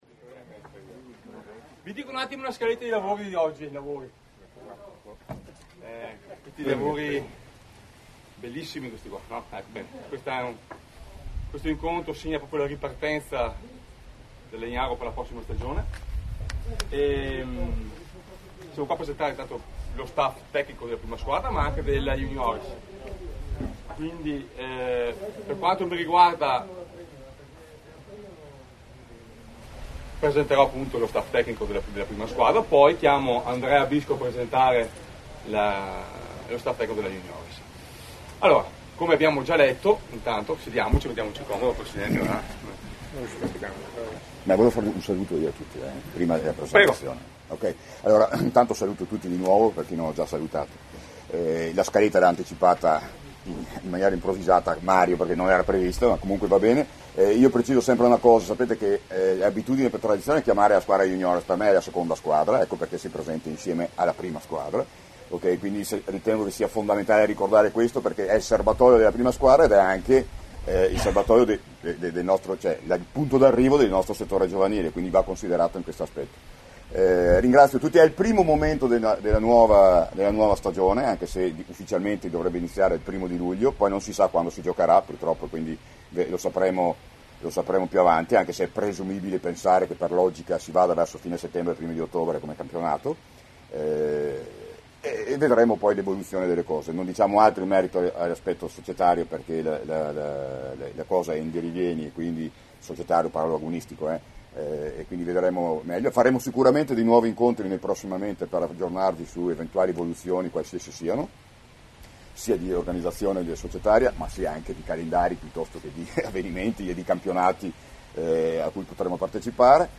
CONFERENZA STAMPA